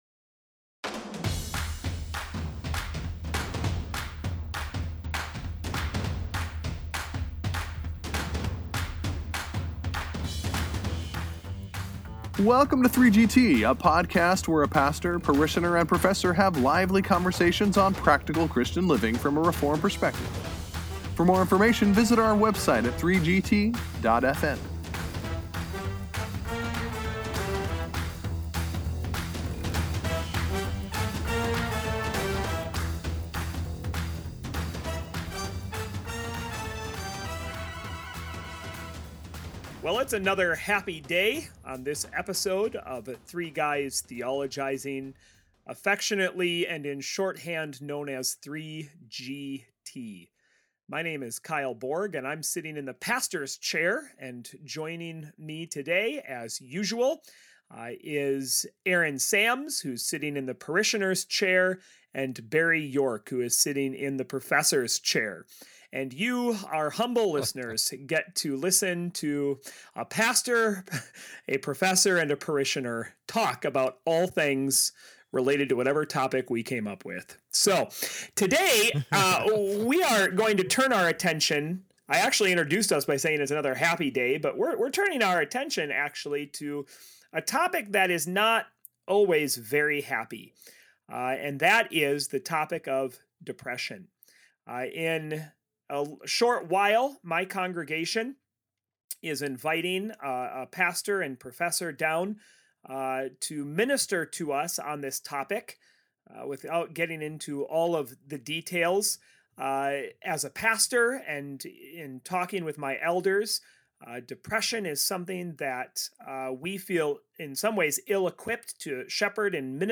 As the guys address these questions, one of them opens up about his own struggles with this issue.